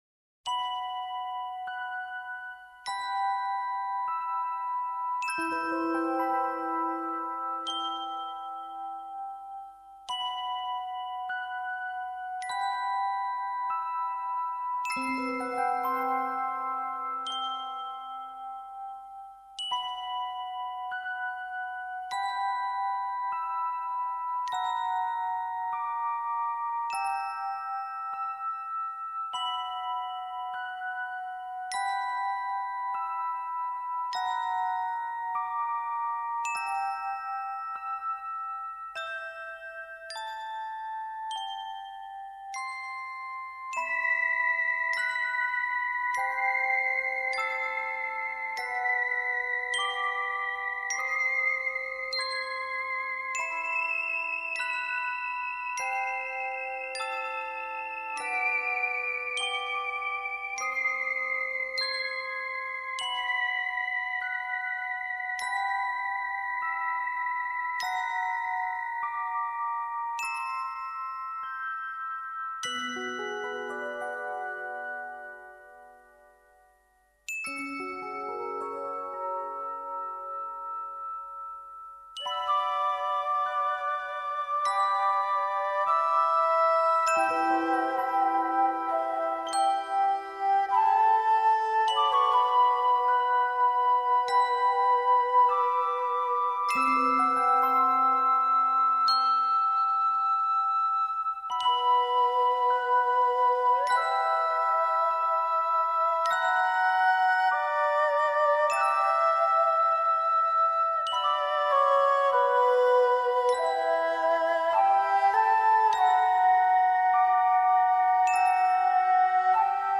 心灵音乐馆
平稳的节拍、抒情的旋律与令人惊喜的清脆音质，敲醒每一颗沈睡的心。